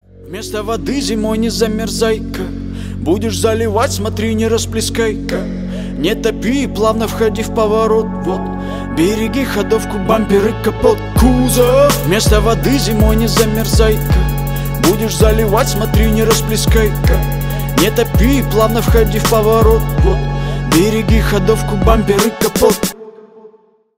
Юмор
кавер
весёлые